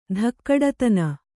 ♪ dhakkaḍatana